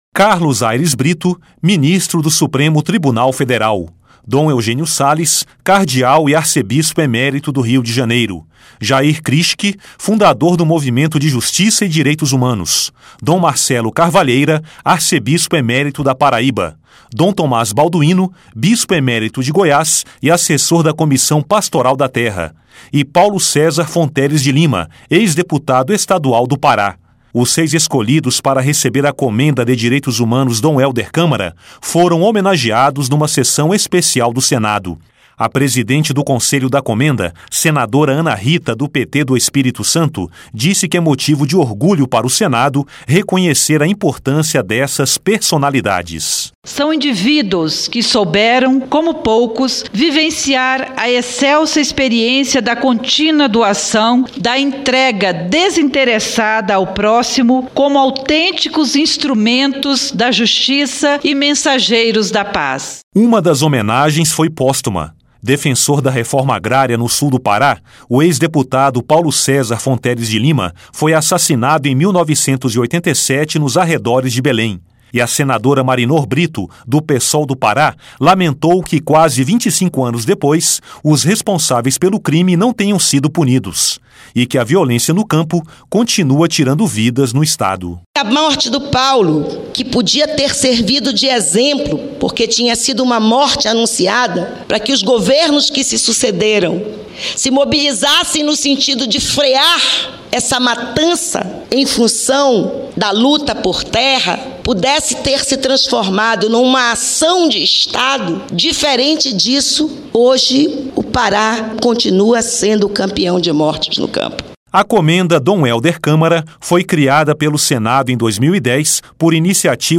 O SENADO HOMENAGEOU NESTA TERÇA-FEIRA SEIS PERSONALIDADES QUE SE DESTACARAM NA DEFESA DOS DIREITOS HUMANOS NO BRASIL. LOC: UMA SESSÃO ESPECIAL NO PLENÁRIO MARCOU A ENTREGA DA COMENDA DOM HÉLDER CÂMARA.
(REPÓRTER)